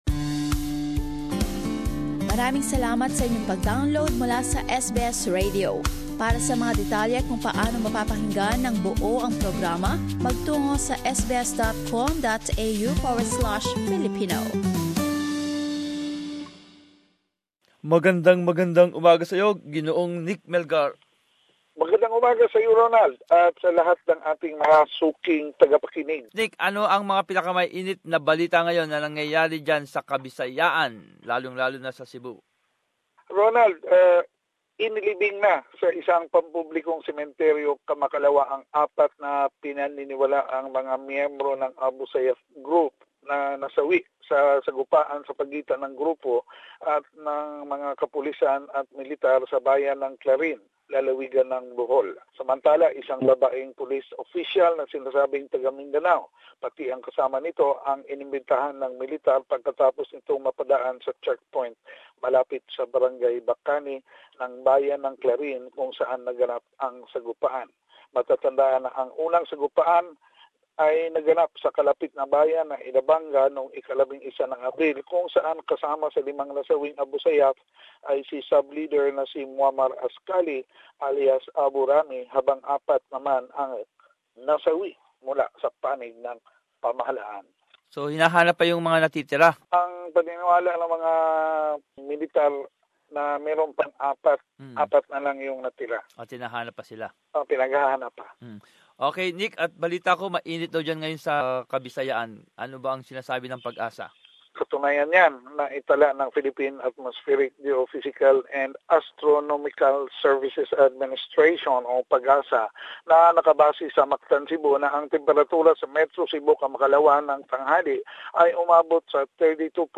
Buod ng mga pinakahuling ulat buhat sa rehiyon